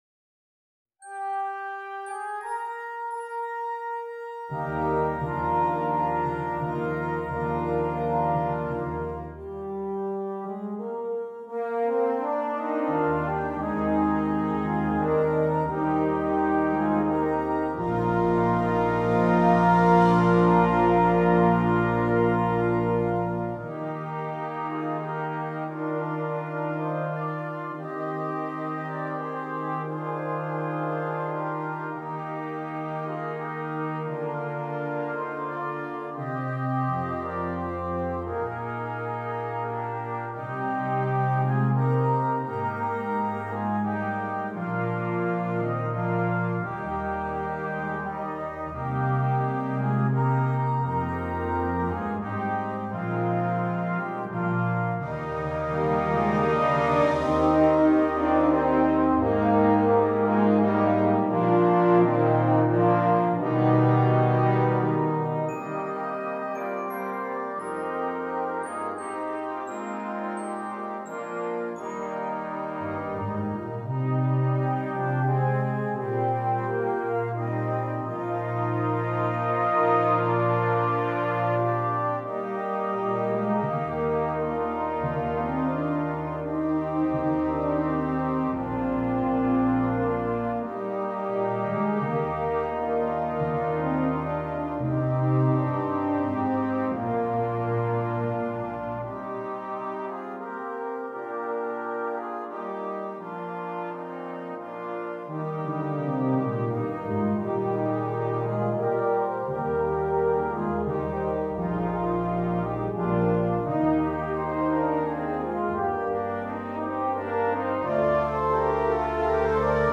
Brass Band
Traditional Spanish Carol